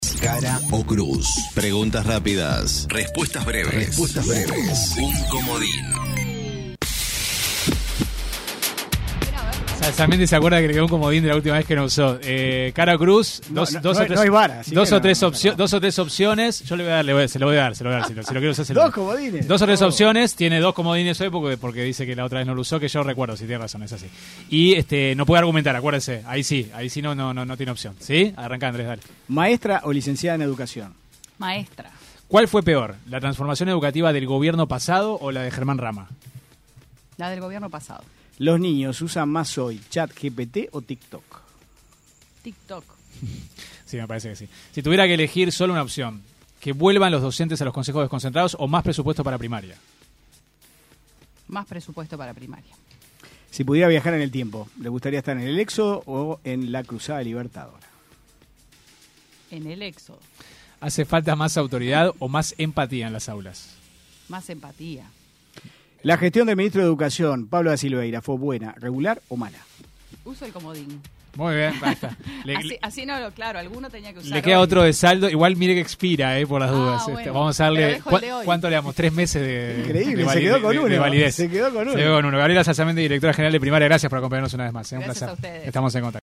En el Cara o Cruz de 970 Noticias, la directora de Primaria, Graciela Salsamendi respondió a las preguntas aquí en 970 Universal